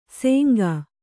♪ sēngā